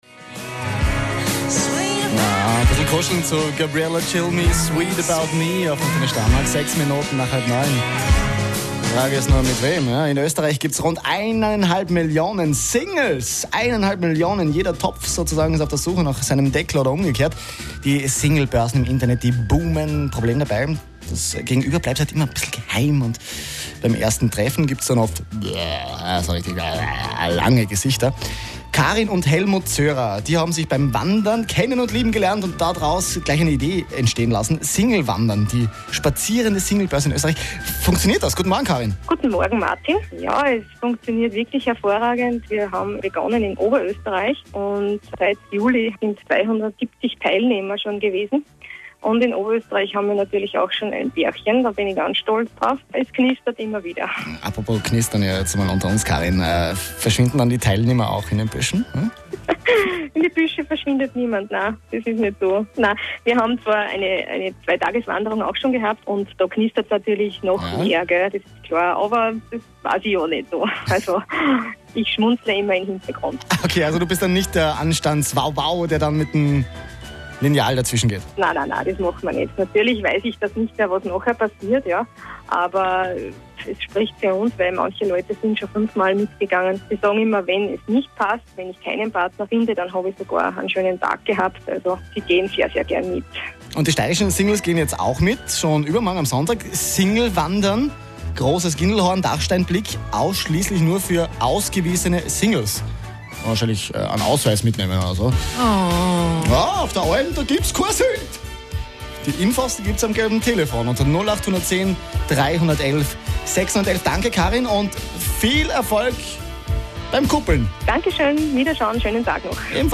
Antenne Steiermark Interview